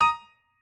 piano8_50.ogg